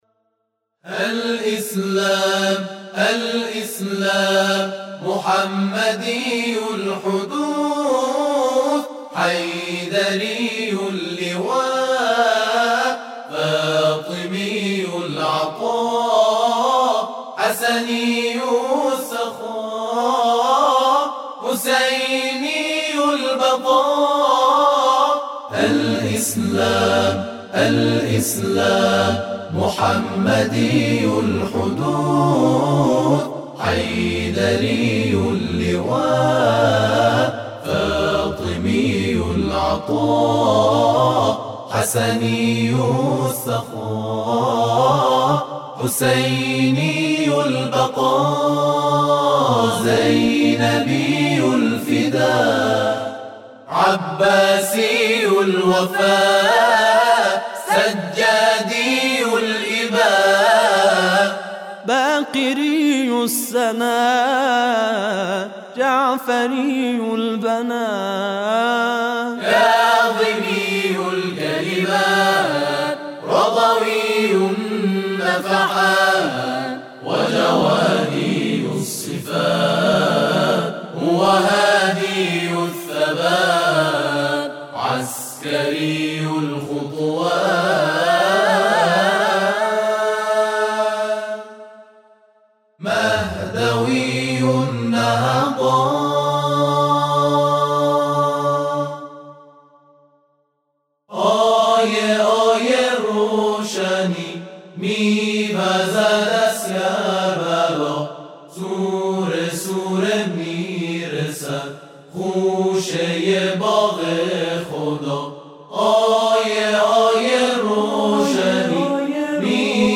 تواشیح الاسلام